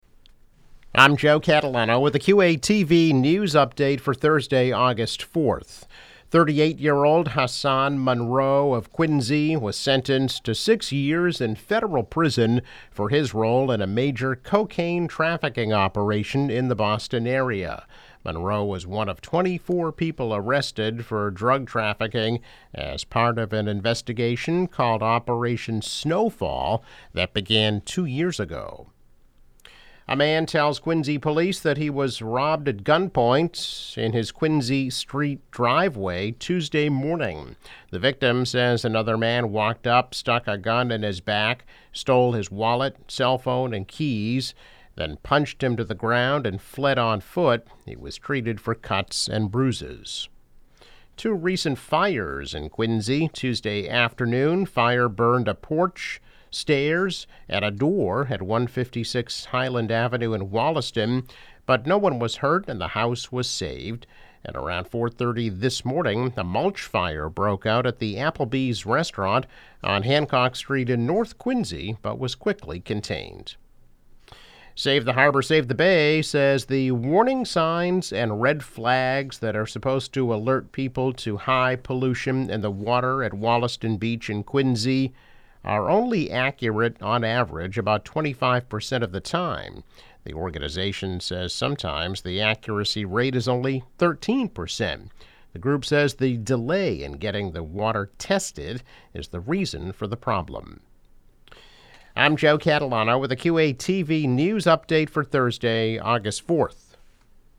News Update - August 4, 2022